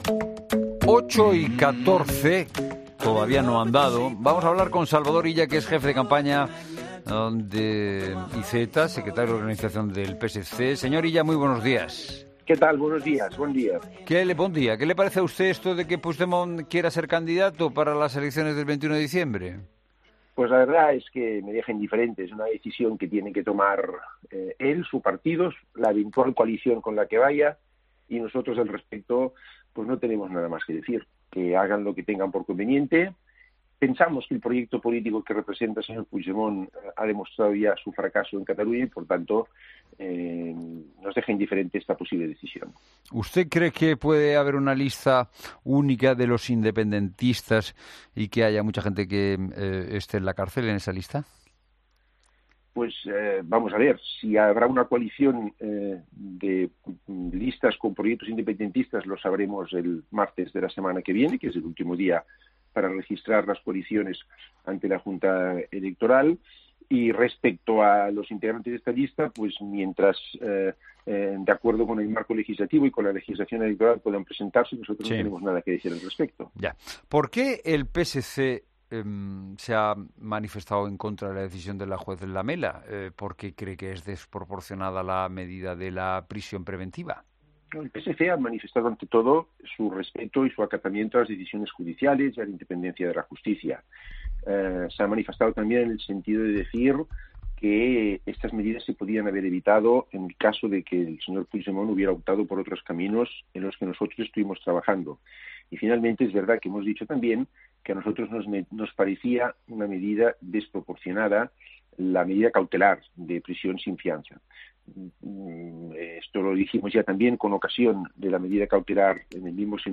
AUDIO: Entrevista al Secretario de Organización del PSC, Salvador Illa.